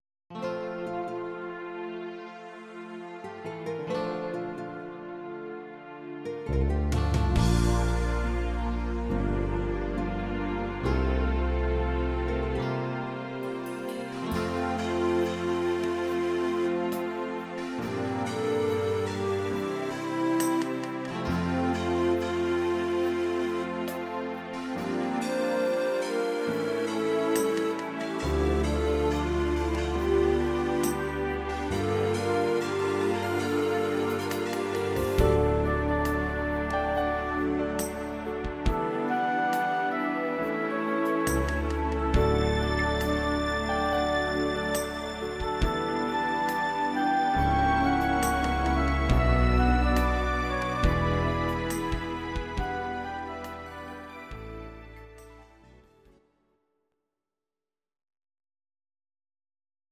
Audio Recordings based on Midi-files
German, Duets